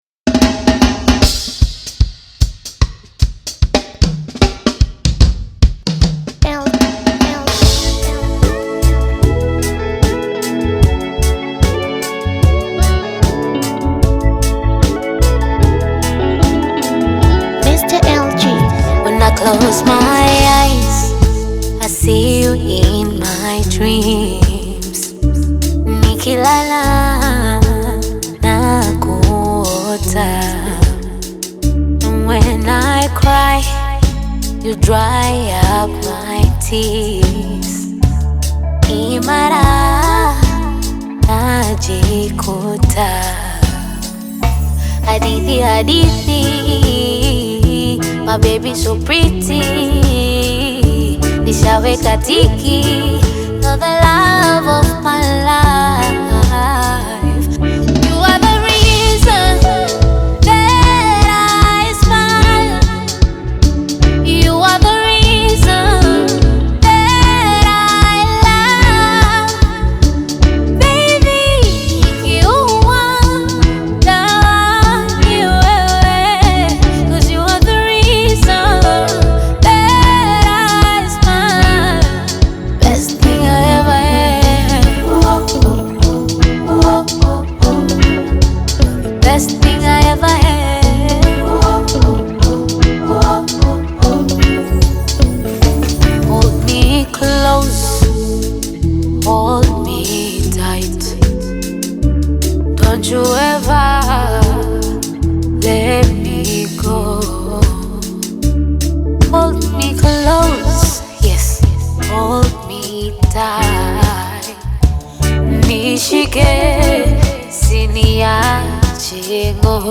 smooth reggae-inspired single